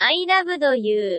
Tags: Star Wars japanese dub